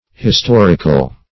\his*tor"ic*al\ (h[i^]s*t[o^]r"[i^]*kal), a. [L. historicus, Gr.